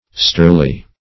Search Result for " souterly" : The Collaborative International Dictionary of English v.0.48: Souterly \Sou"ter*ly\, a. Of or pertaining to a cobbler or cobblers; like a cobbler; hence, vulgar; low.